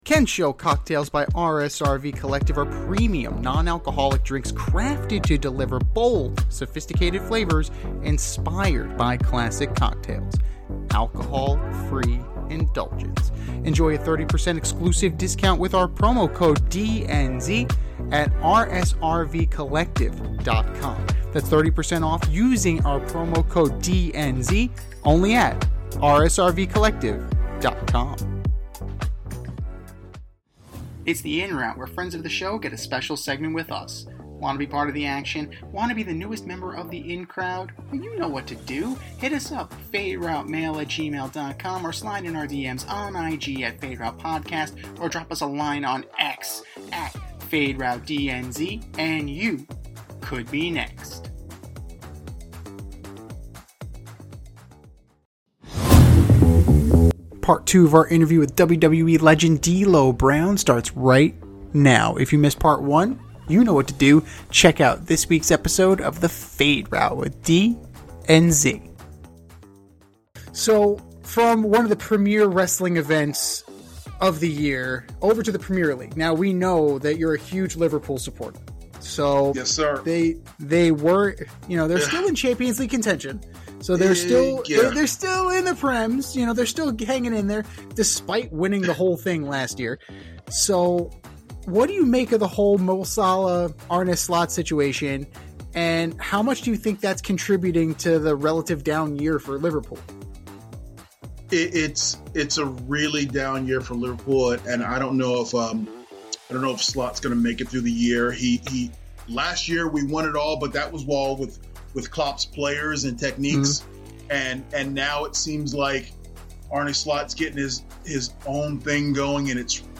In part two of our interview, the OG Eurocontinental Champion, former WWE and Impact Wrestling superstar, road agent, and color commentator D'Lo Brown discusses his sports interests beyond the squared circle, like Liverpool's struggles in the English Premier League and the Bears' outlook for next season.